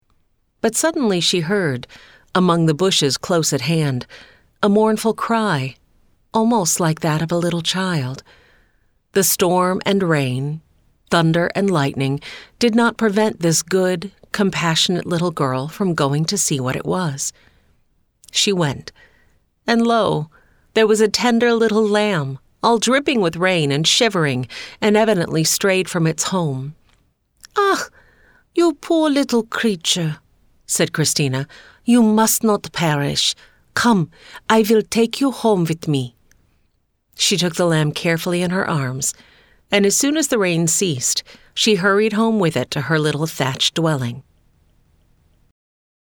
The-Little-Lamb-Audiobook-Sample.mp3